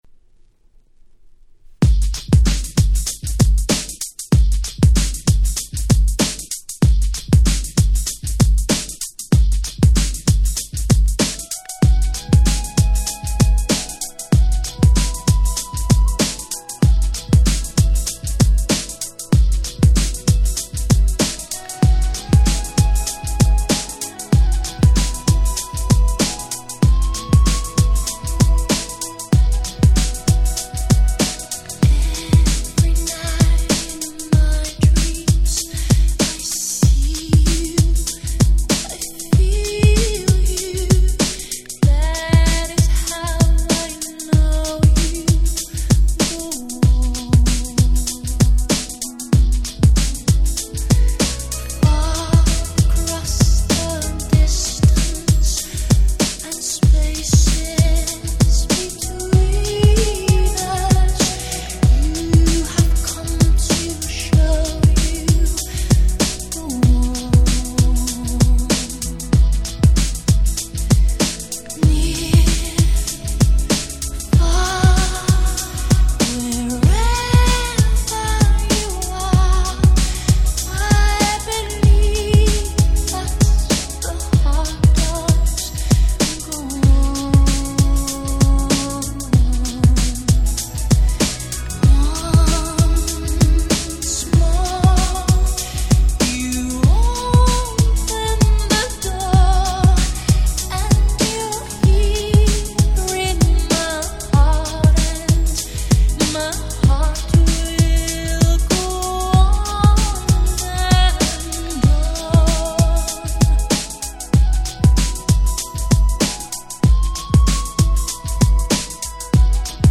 White Press Only Remix !!